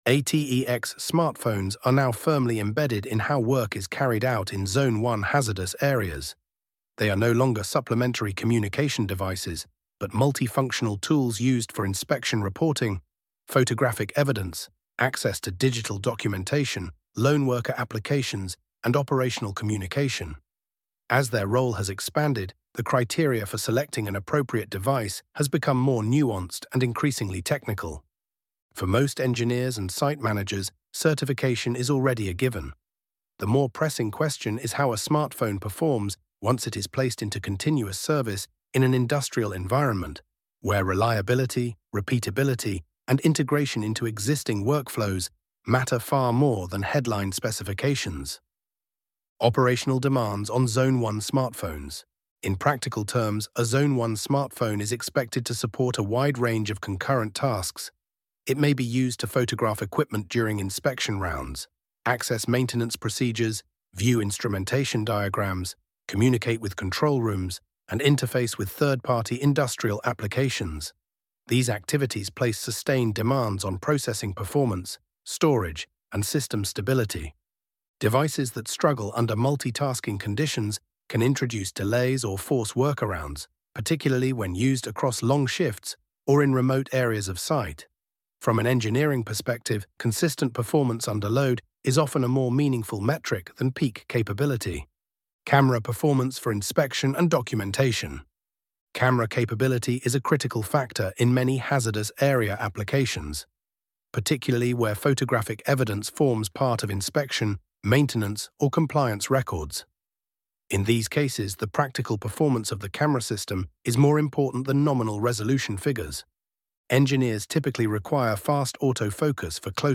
ElevenLabs_Choosing_the_Right_ATEX_Smartphone_for_.mp3